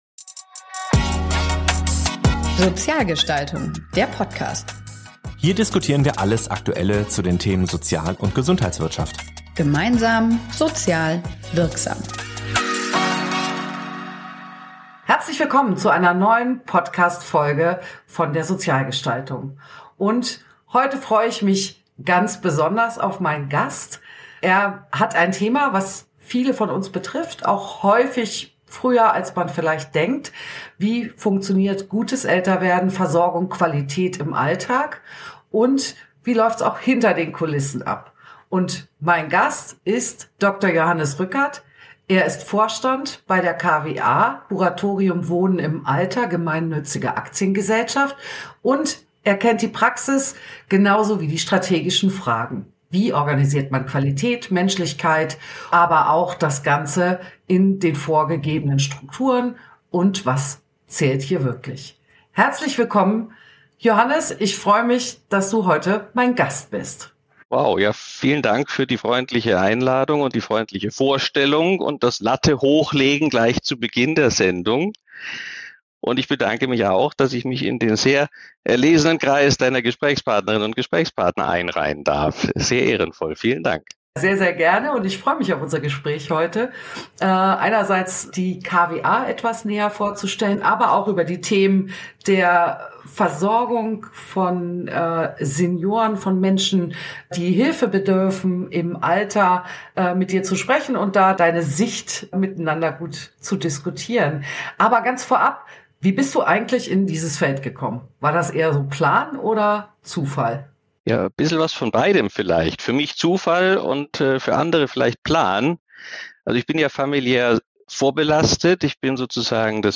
Komfort, Innovation, Pflege: Was macht Wohnstifte zum Hidden Champion? Gespräch